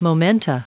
Prononciation du mot momenta en anglais (fichier audio)
Prononciation du mot : momenta